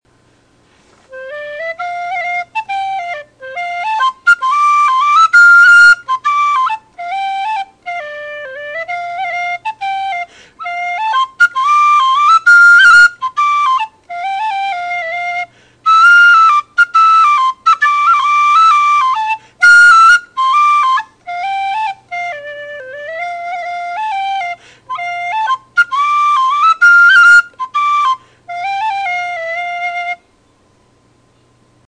Tully Soliloquy Soprano C whistle
Non-pure with a nice chiffy character.
Sound clips of the whistle: